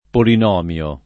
polinomio [ polin 0 m L o ]